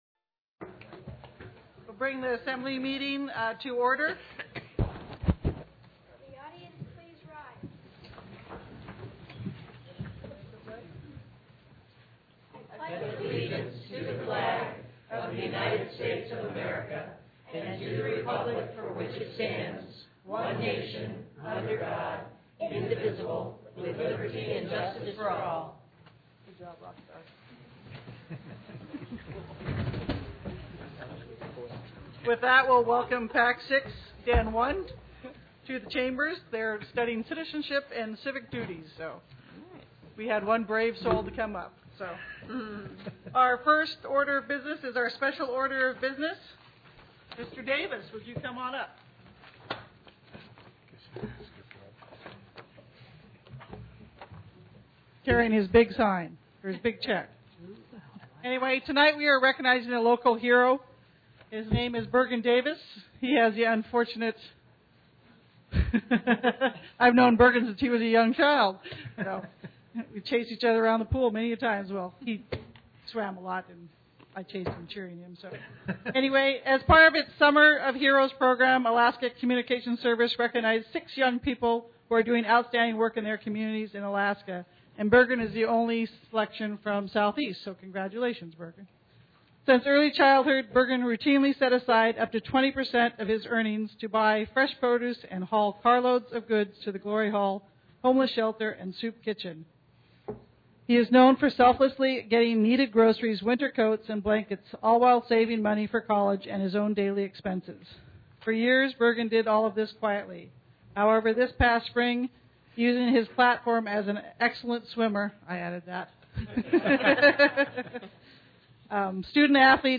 The City and Borough of Juneau Assembly’s regular meeting on Dec. 17, 2018.